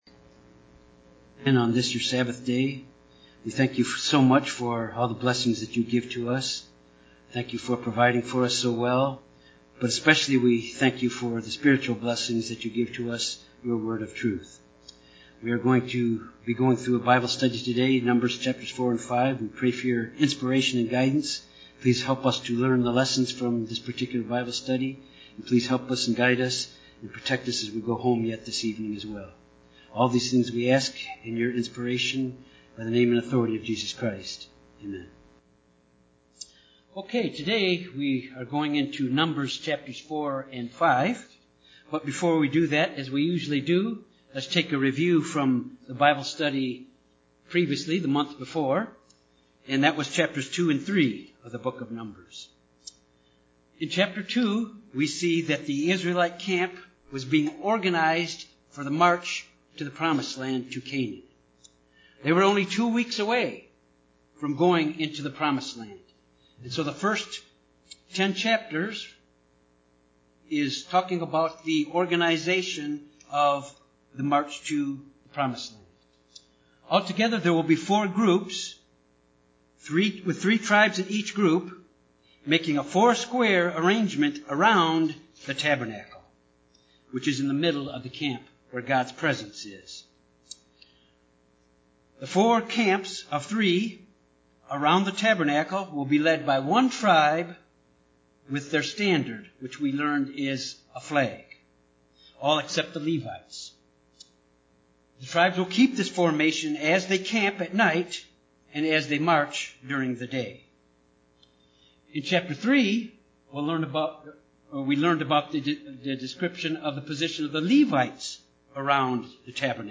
This Bible study covers the duties of the different orders of the Levites and their responsibilities in handling the furnishing of the tabernacle. Also examined is the test for marital unfaithfulness of man’s wife in a male dominated system.